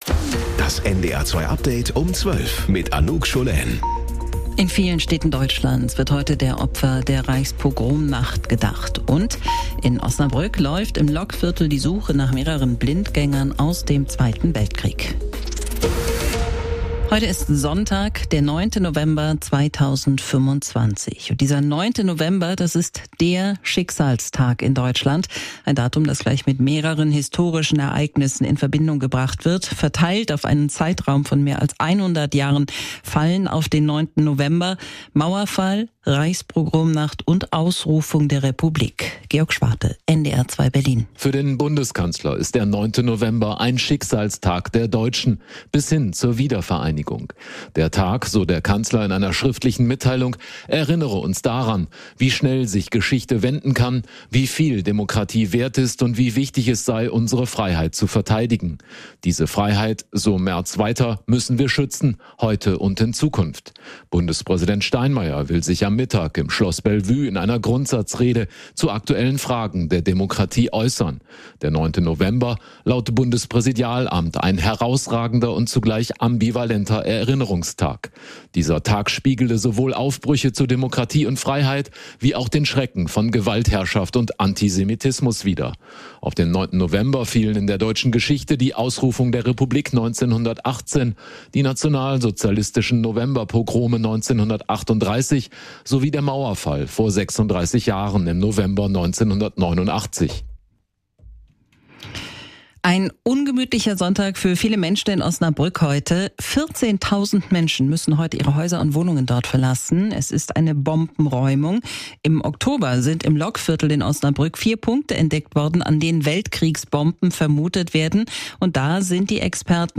Mit unseren Korrespondent*innen und Reporter*innen, im Norden, in Deutschland und in der Welt.